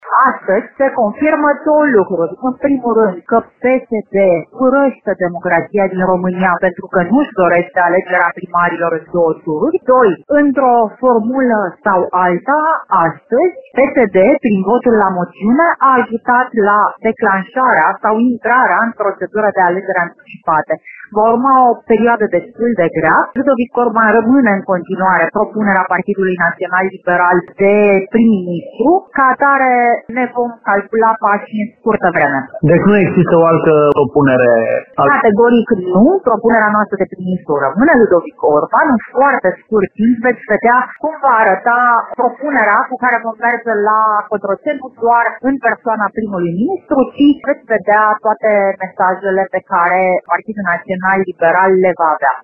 La rândul său, senatorul PNL de Timiș, Alina Gorghiu, a declarat că Partidul Naţional Liberal era pregătit şi pentru scenariul trecerii moţiunii, iar propunererea formaţiunii pentru formarea unui nou Guvern este tot Ludovic Orban.